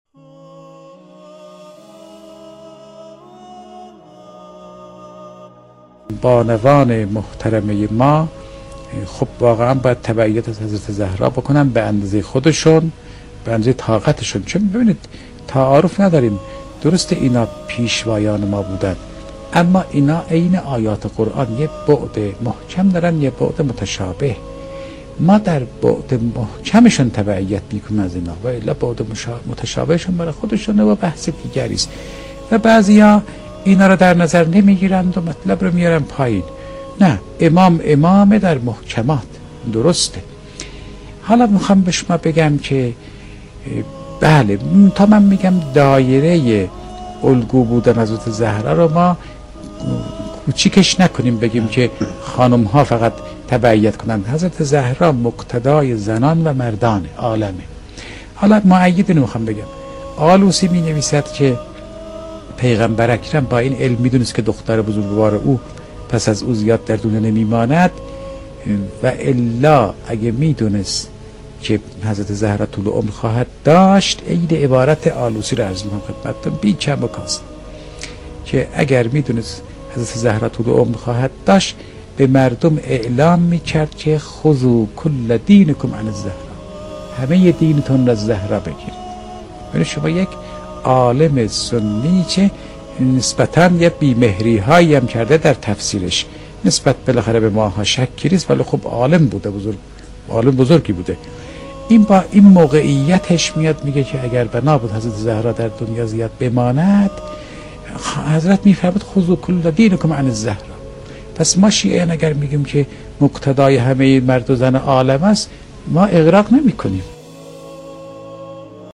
در ادامه سخنرانی آیت‌الله فاطمی‌نیا با موضوع «فاطمه(س)، الگوی آزادگان عالم» تقدیم مخاطبان گرامی ایکنا می‌شود.